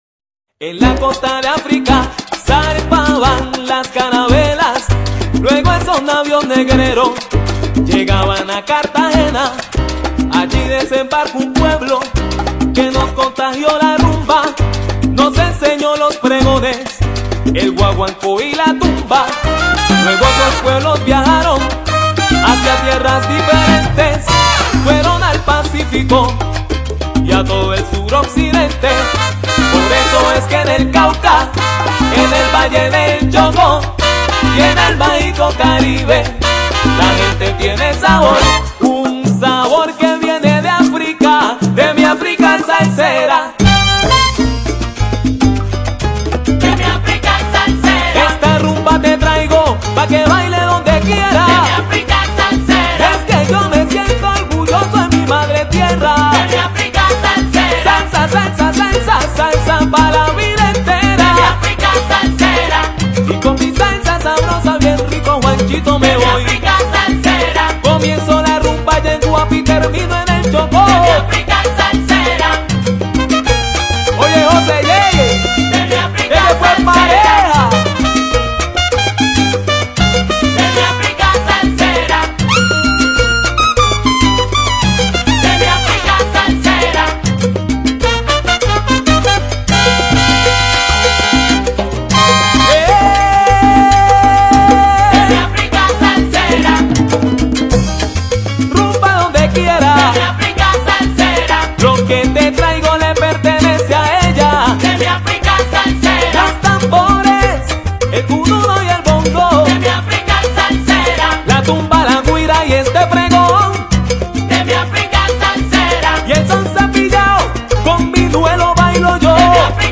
cubasalsa.mp3